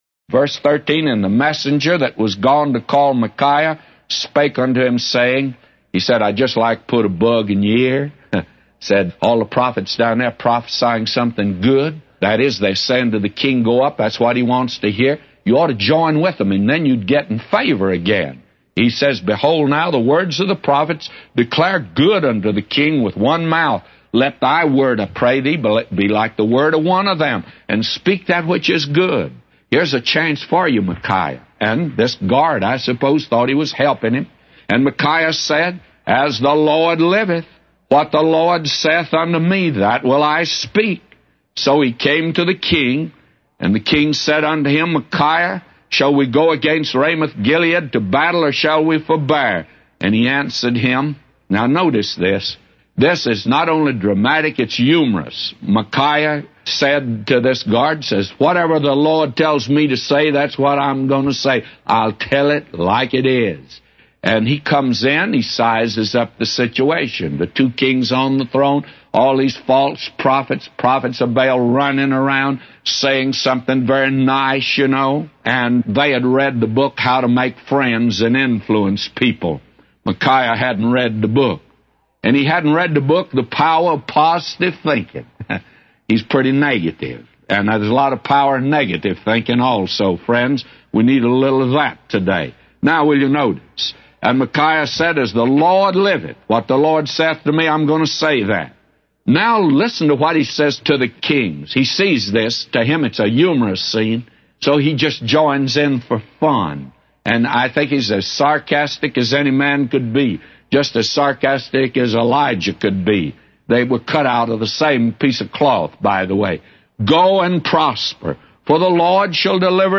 A Commentary By J Vernon MCgee For 1 Kings 22:13-999